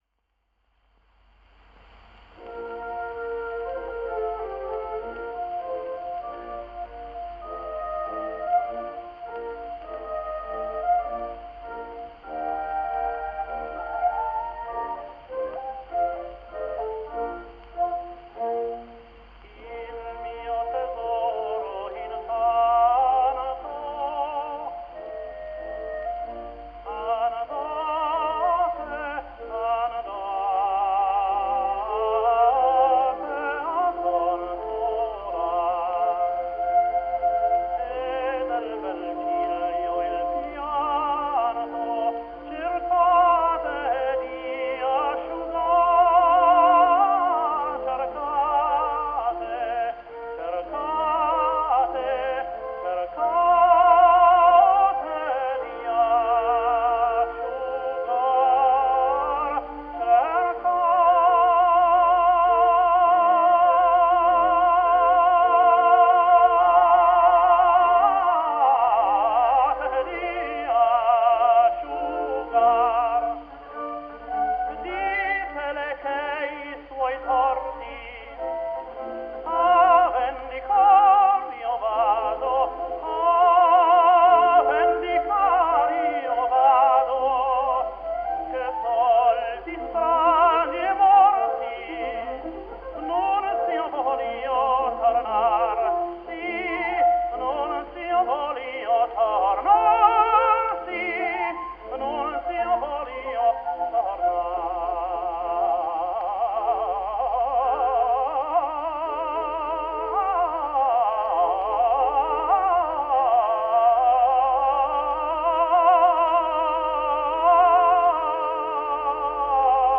John McCormack | Irish Tenor | 1884 - 1945 | History of the Tenor